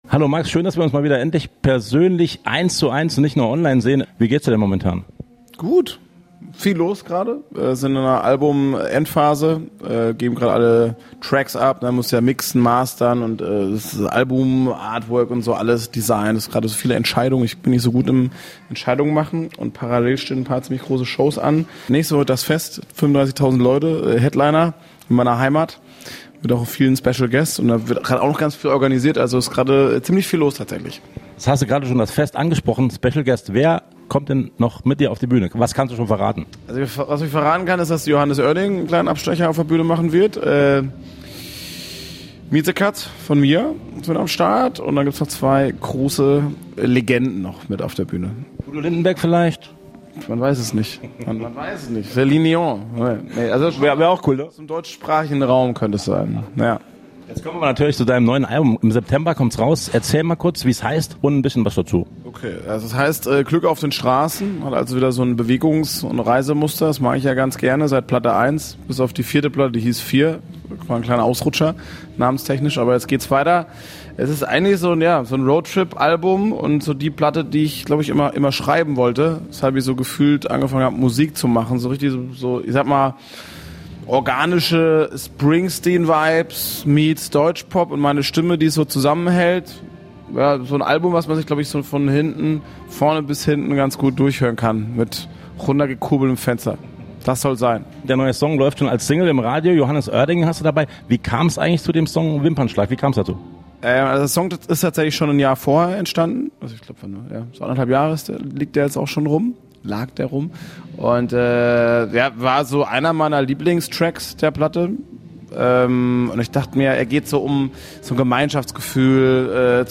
Max Giesinger im RADIO SALÜ Interview!